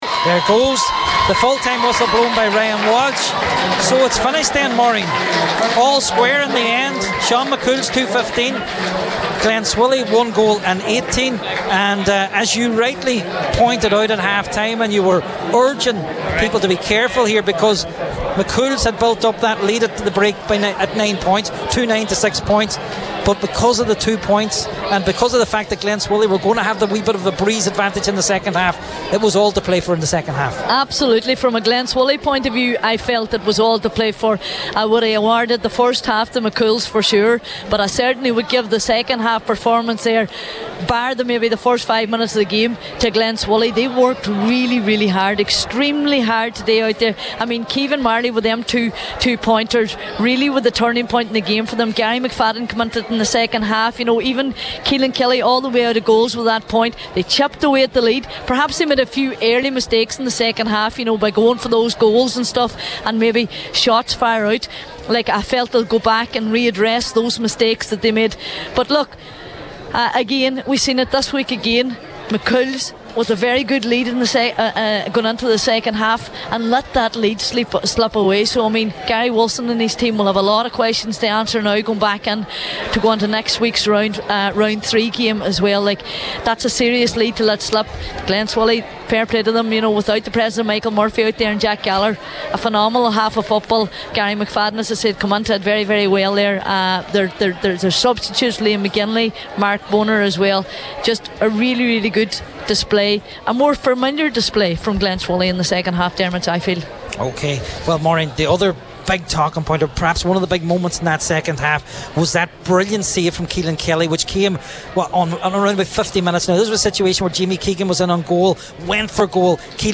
live for Highland Radio Sunday Sport at full time…